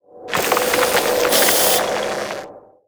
gust.wav